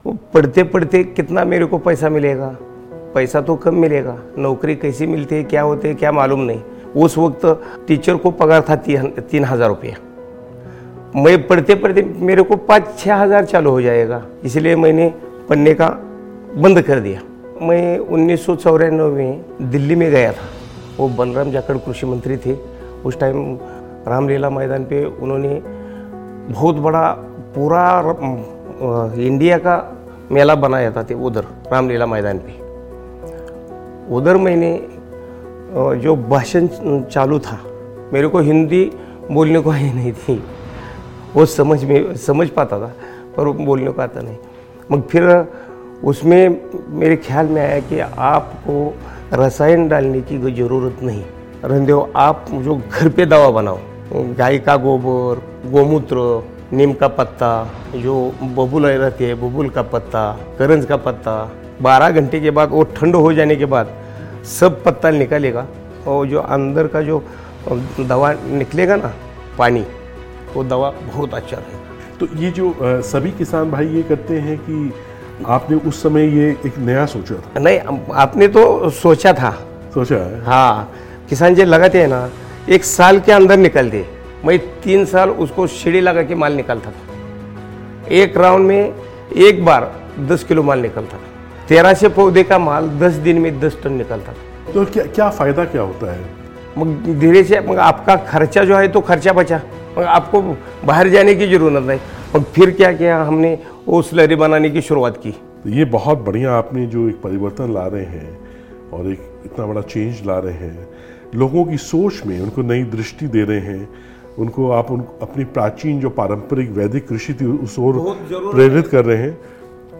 This episode offers a deep dive into her experiences, challenges, and the transformative role of journalism in shaping society. Don't miss this insightful discussion!